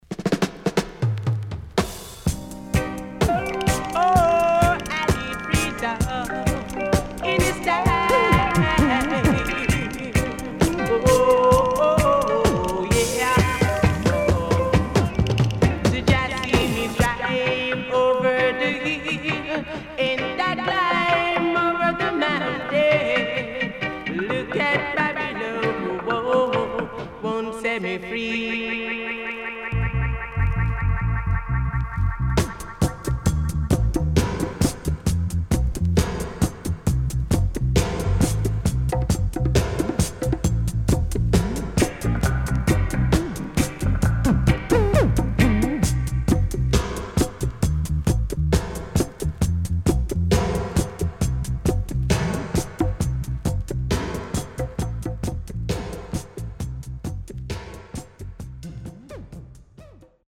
Early 80's.Killer Roots Vocal & Dubwise
少しチリノイズ入りますが良好です。